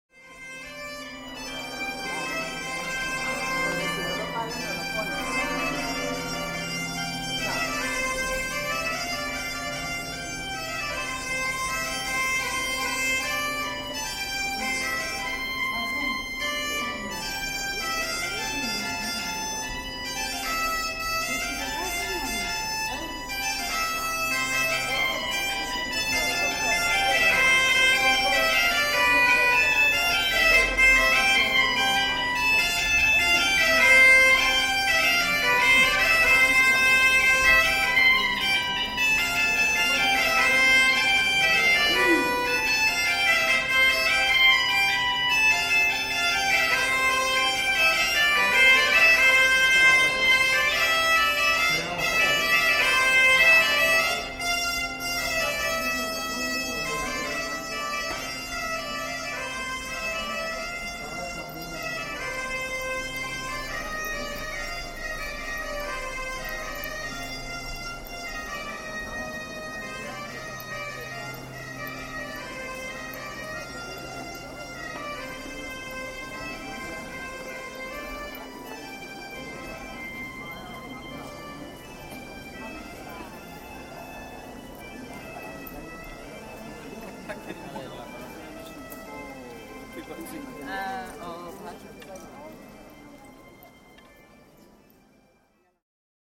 Gaita Galega
As we walk the historic streets of Santiago de Compostela, the sound of passing bagpipers, playing the distinctive 'gaita galega' fills the air, carrying the weight of centuries of tradition. The deep, resonant drones mix with the high, piercing melodies, the unique conical pipe creating a rich and evocative sonic space. Galician bagpipes, present in the region’s iconography since medieval times, weave through the cobblestone streets, their music echoing off the historic buildings. The melody flows in and out as we move, with each note fading in and out of earshot, creating a shifting, dynamic soundtrack to our walk.